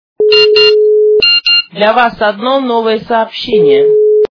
- звуки для СМС
При прослушивании Женский голос - Для Вас одно новое сообщение качество понижено и присутствуют гудки.
Звук Женский голос - Для Вас одно новое сообщение